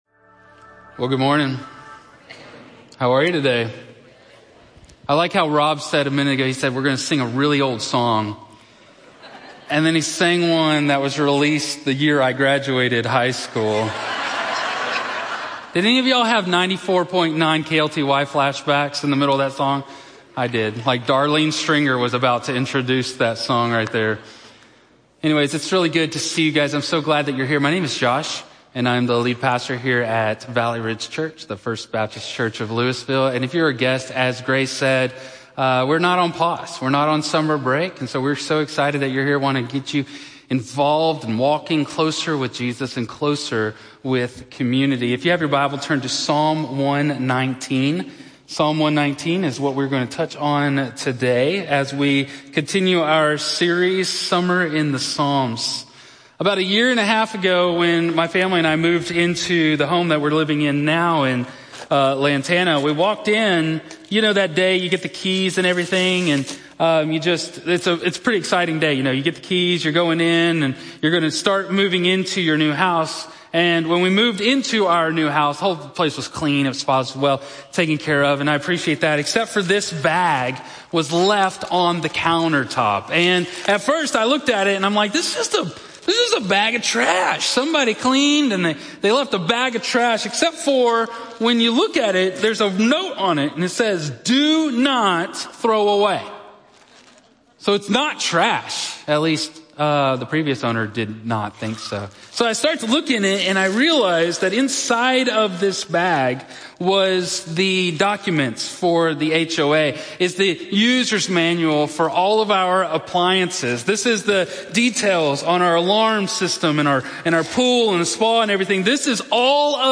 In this powerful exploration of Psalm 119, we're challenged to truly “keep the instructions” of God's Word. The sermon delves into three key aspects: storing God's Word in our hearts, obeying it in our daily lives, and seeking to understand it deeply.